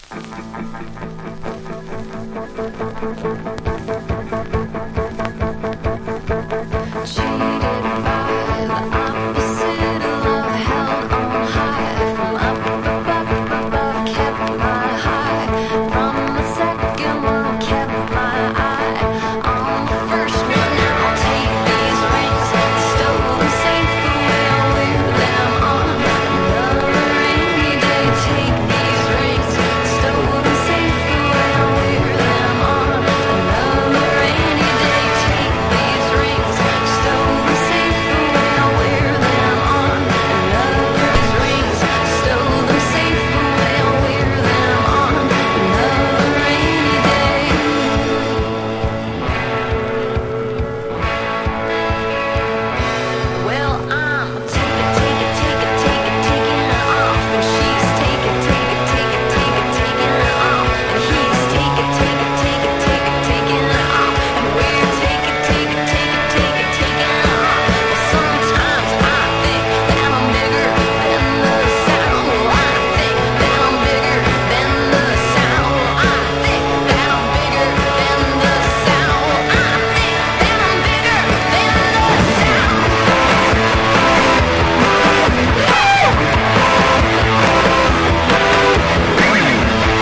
1. 00S ROCK >
ALTERNATIVE / GRUNGE